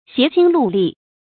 协心戮力 xié xīn lù lì
协心戮力发音